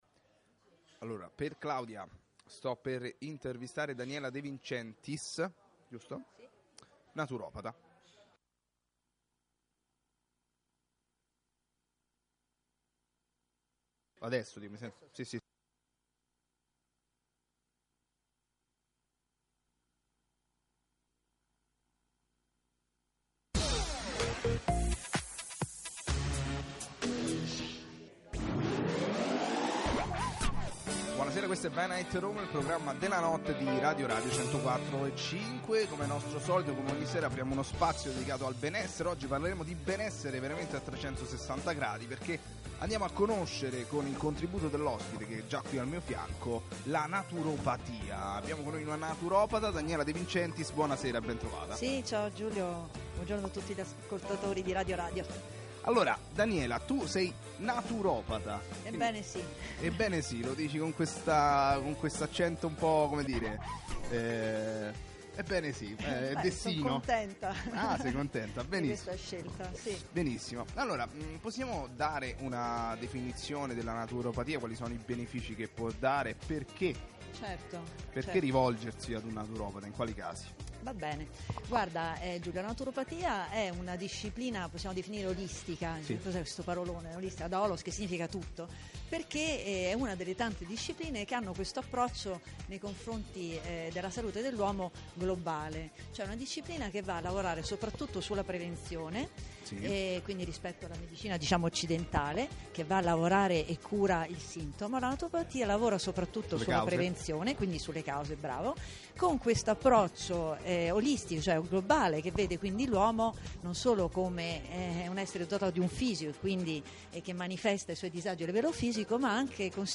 INTERVISTA A RADIO RADIO (104.5) del 2019